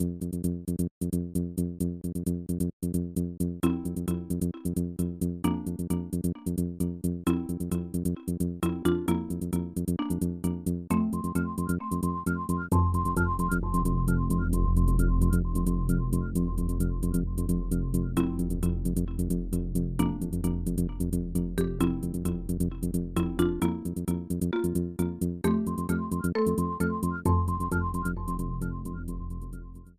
Music theme